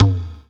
VEC3 Percussion 006.wav